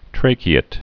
(trākē-ĭt)